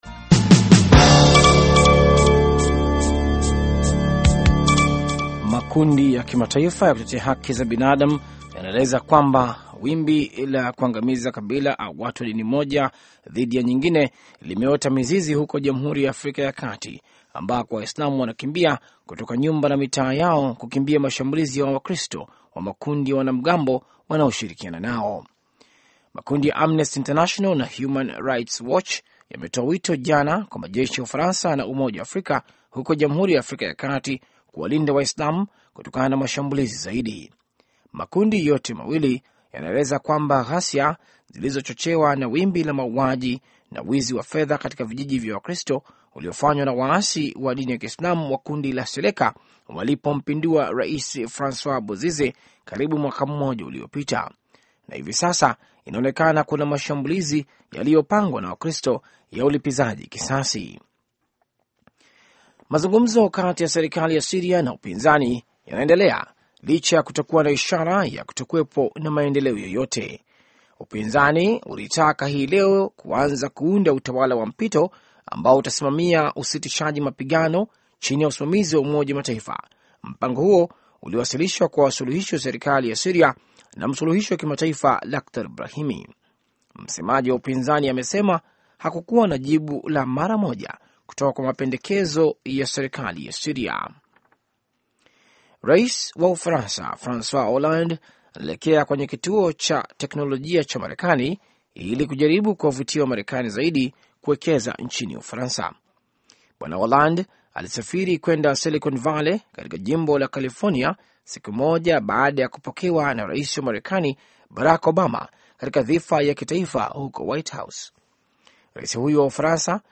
Taarifa ya Habari VOA Swahili - 6:05